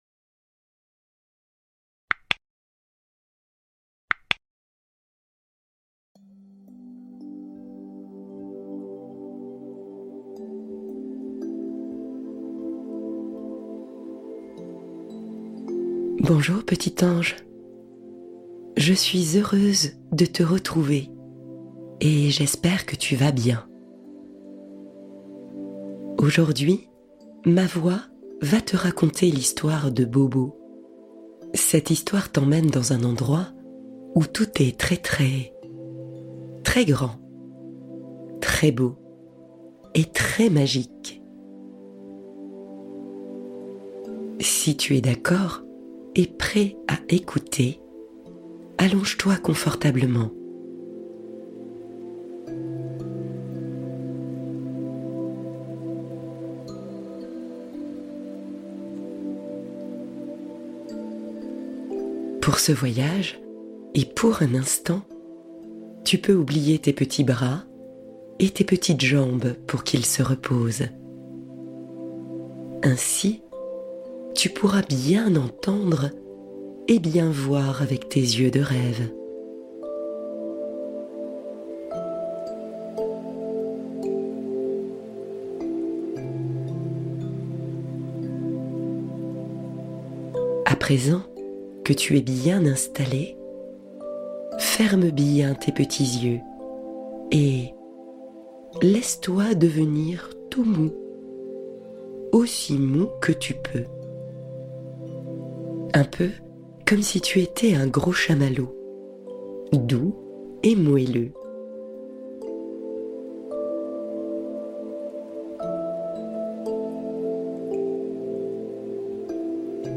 Voyage Stellaire : Méditation complice entre parents et enfants